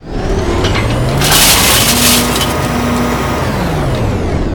metalgather.ogg